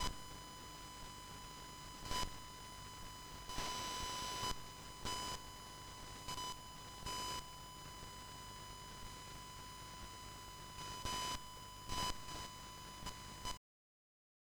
ringing, buzzes and squeaks when connected to devices with their own power supply
When my audio interface (Scarlett 2i2) is connected to any device with a power supply independent of my computer, such as my bass amp, it captures ringing, buzzes and squeaks like this
That's buss noise, and is the data inside your computer getting onto the ground system.